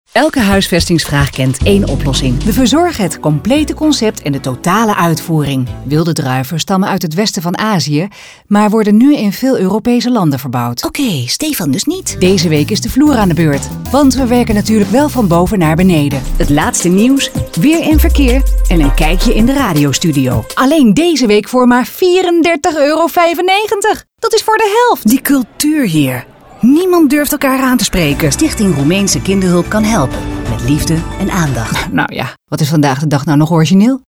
Haar communicatie stijl is nuchter, trefzeker, warm en betrokken; to-the-point.
Accentloos, helder en met de juiste toon voor jouw doelgroep.